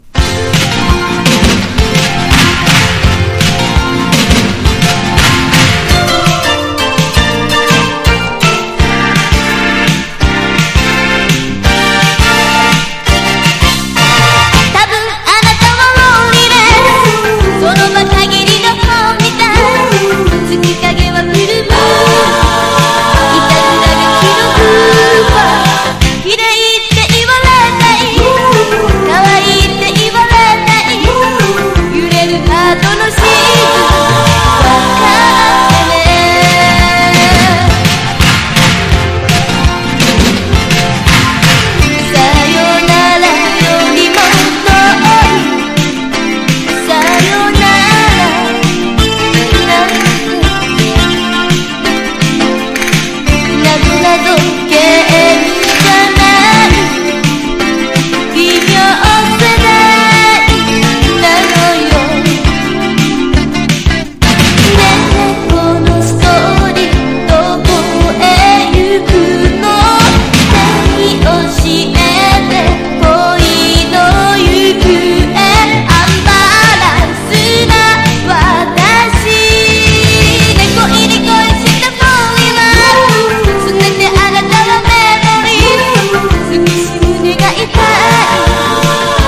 POP
ポピュラー# SOUNDTRACK